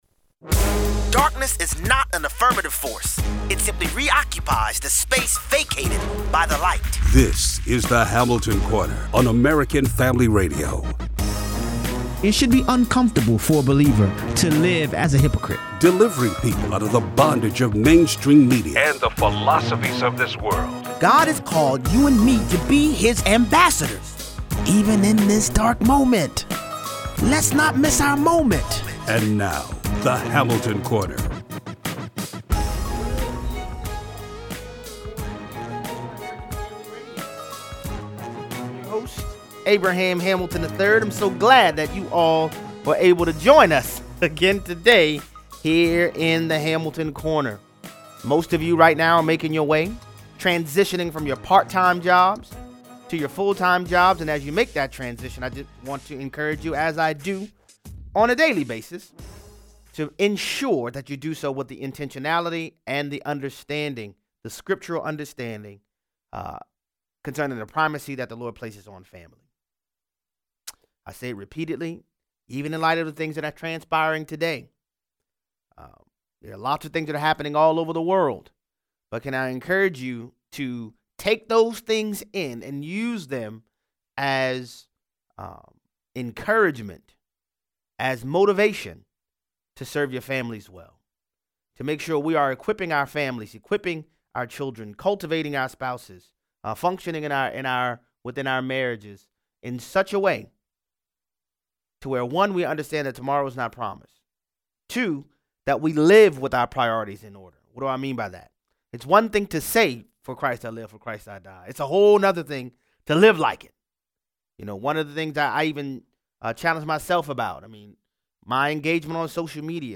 President Trump’s entire speech from this morning following Iran’s attack on military bases in Iraq that housed U.S. servicemen and women. 38:00 - 54:30.